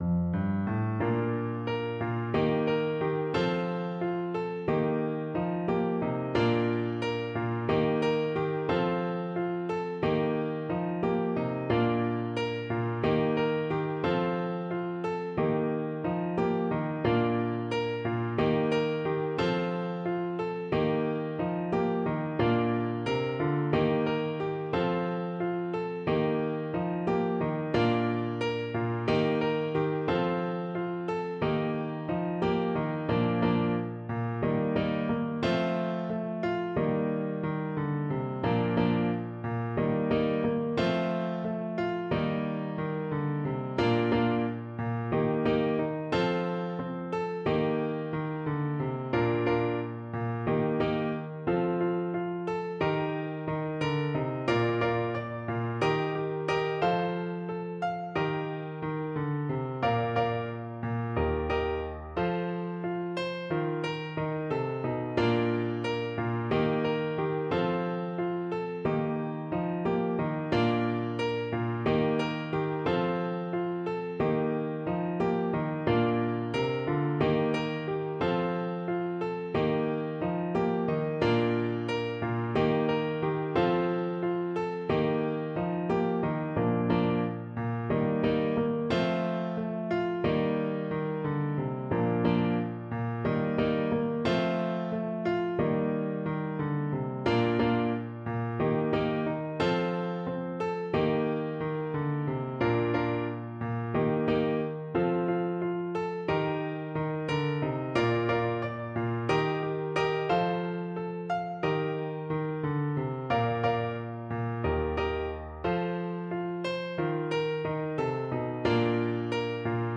Traditional Trad. Guantanamera Clarinet version
Play (or use space bar on your keyboard) Pause Music Playalong - Piano Accompaniment Playalong Band Accompaniment not yet available transpose reset tempo print settings full screen
4/4 (View more 4/4 Music)
With energy = c.132
Bb major (Sounding Pitch) C major (Clarinet in Bb) (View more Bb major Music for Clarinet )
Clarinet  (View more Intermediate Clarinet Music)
Traditional (View more Traditional Clarinet Music)
world (View more world Clarinet Music)
guantanameraCL_kar3.mp3